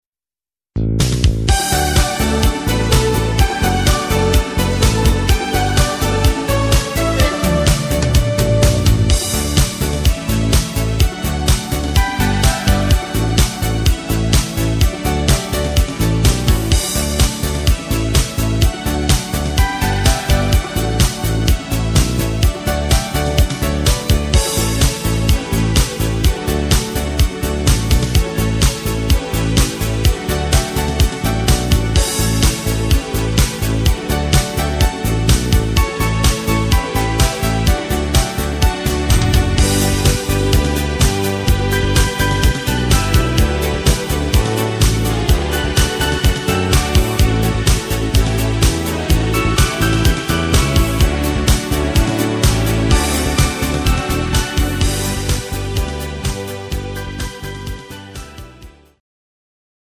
Discofox-Party-Version